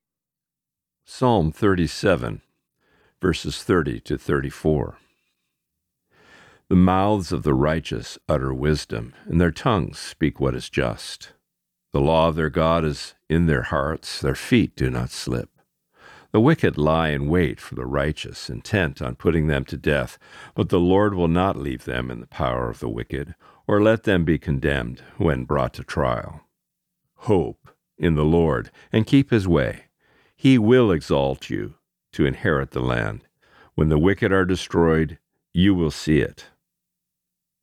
Reading: Psalm 37:30-34